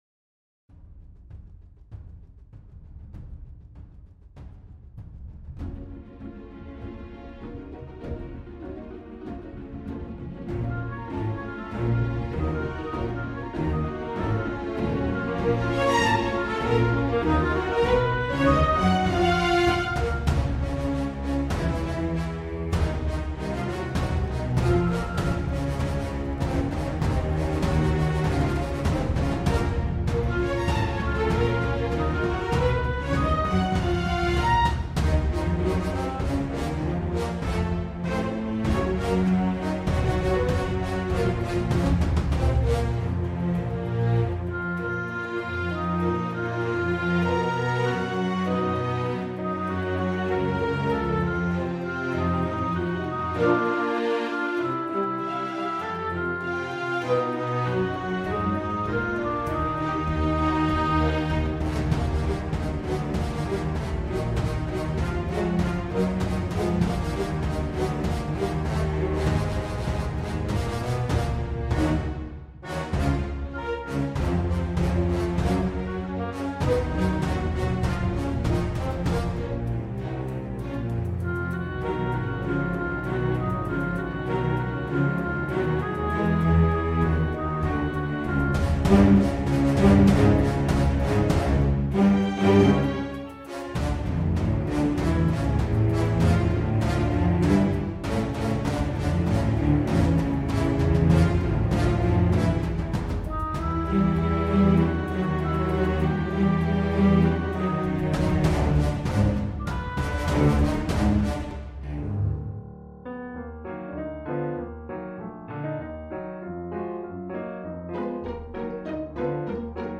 genre:orchestral